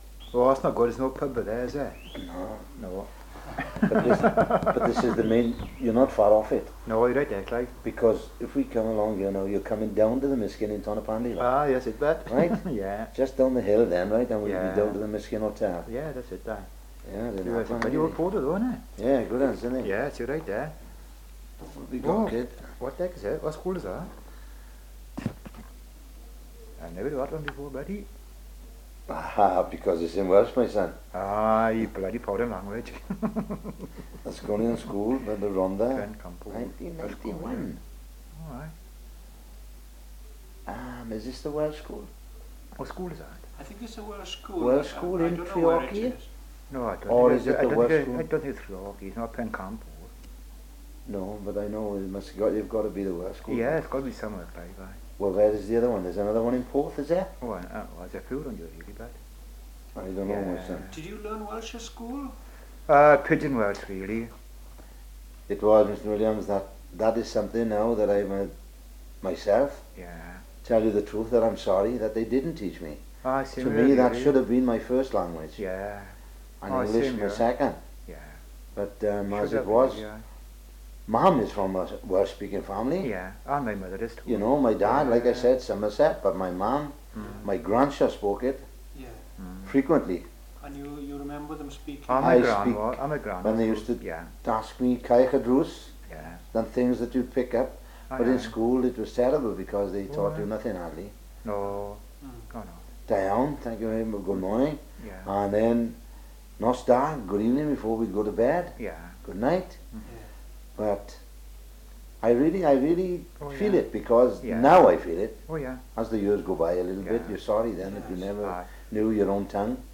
Treherbert10Conversation.mp3